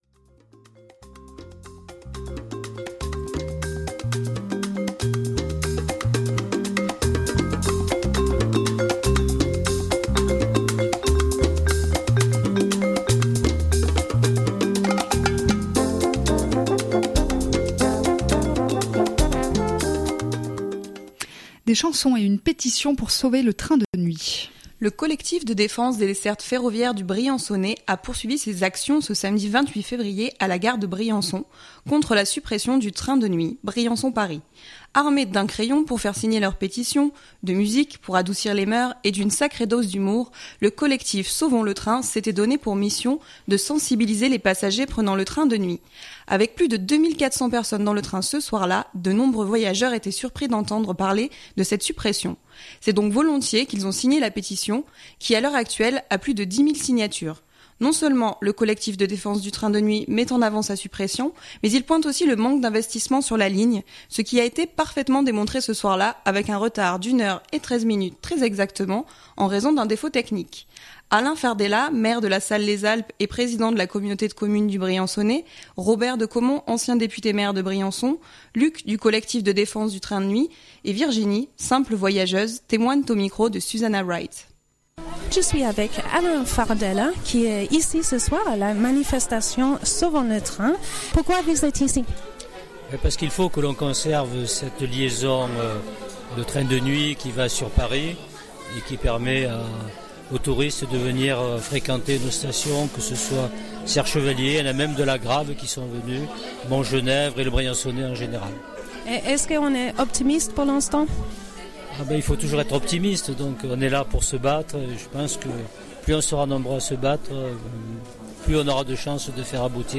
Le collectif de défense des dessertes ferroviaires du Briançonnais a poursuivi ses actions ce samedi 28 février à la gare de Briançon, contre la suppression du train de nuit Briançon/Paris.